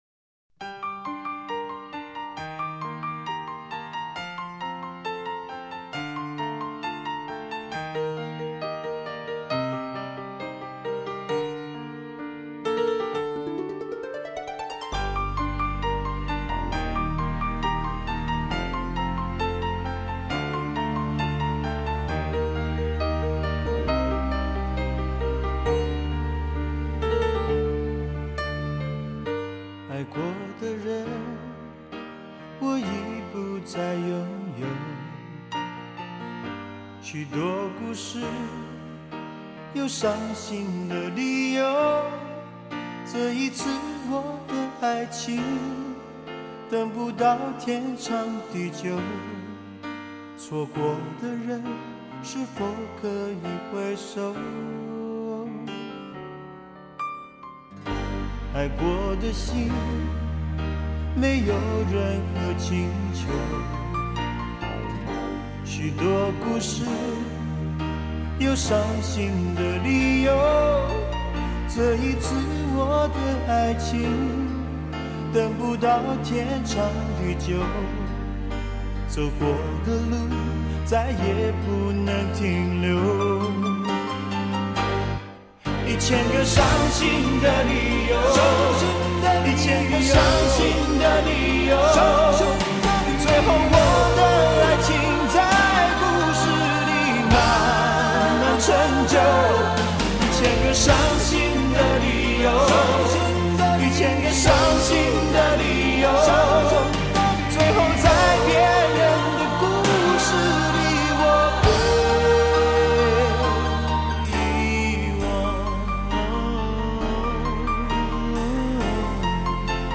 音色完美